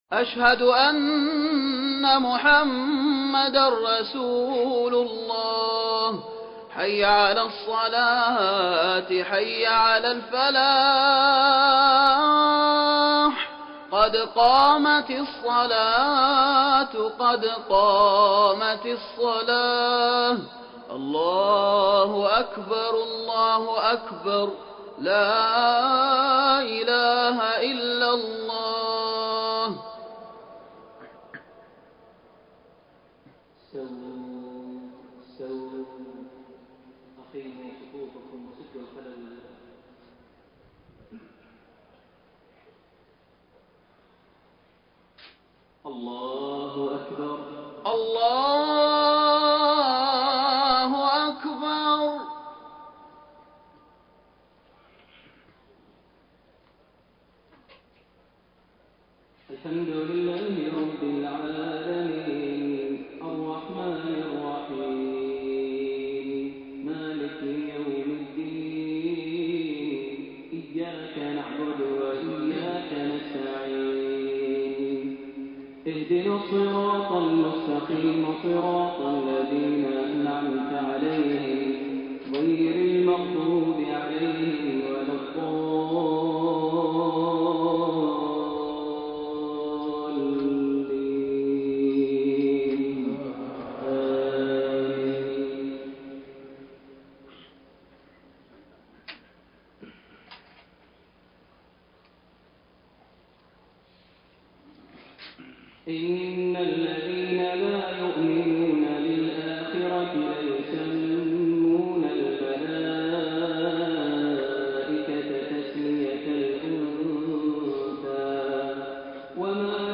صلاة المغرب 8-2-1433 من سورة النجم 27-47 > 1433 هـ > الفروض - تلاوات ماهر المعيقلي